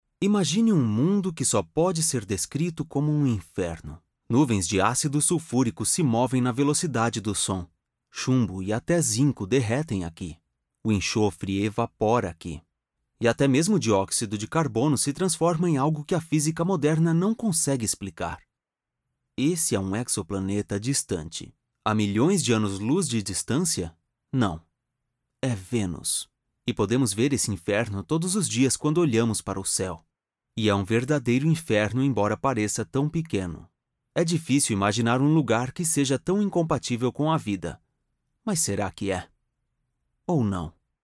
Documentales
Neumann TLM193 Microphone
Acoustic and soundproof Booth
BarítonoBajo